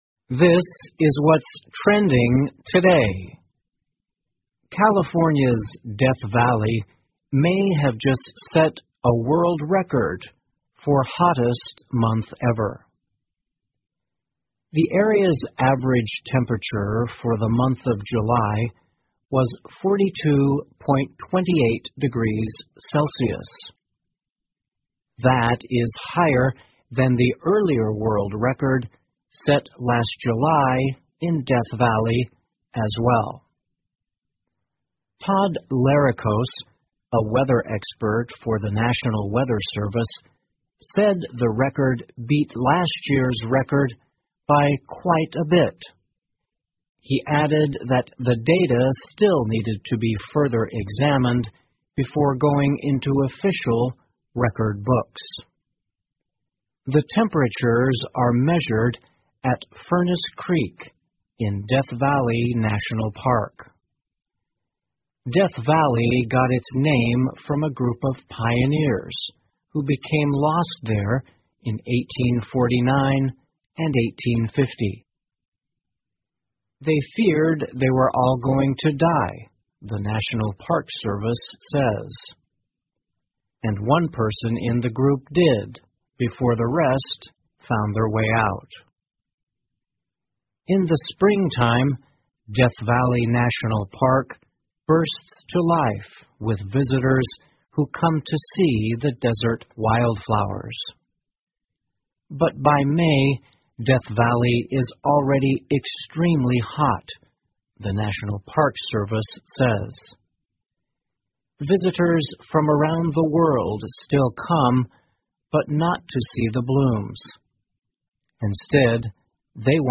VOA慢速英语--加州死亡谷创下最热月份新纪录 听力文件下载—在线英语听力室